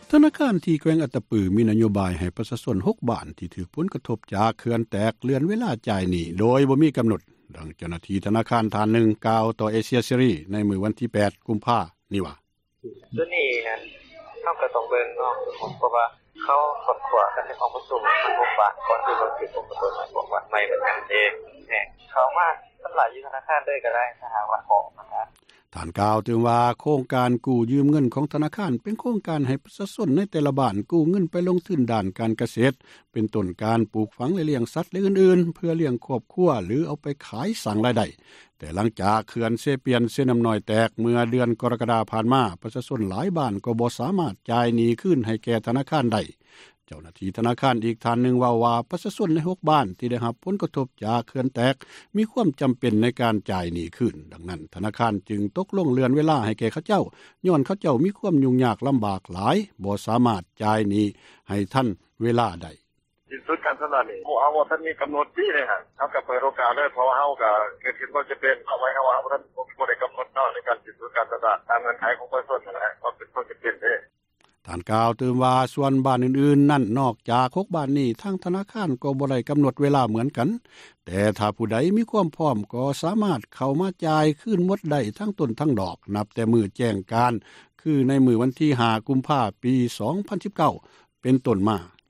ທະນາຄານ ທີ່ແຂວງອັດຕະປື ມີນະໂຍບາຍ ໃຫ້ປະຊາຊົນ 6 ບ້ານ, ທີ່ຖືກຜົລກະທົບຈາກເຂື່ອນແຕກ ເລື່ອນເວລາຈ່າຍໜີ້ ໂດຍບໍ່ມີກຳນົດ. ດັ່ງເຈົ້າຫນ້າທີ່ທະນາຄານທ່ານນຶ່ງ, ກ່າວຕໍ່ເອເຊັຽເສຣີ ໃນມື້ວັນທີ 8 ກຸມພາ ນີ້ວ່າ: